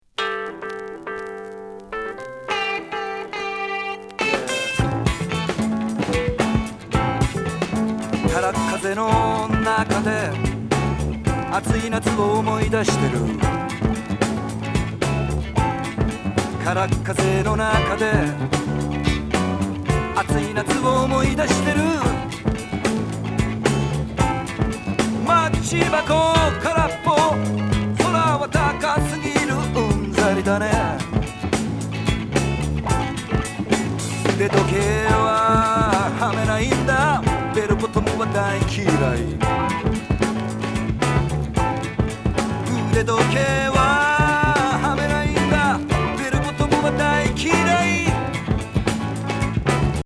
ハモンド効いたファンキー・フォーク・グルーヴ